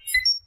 countdown.ogg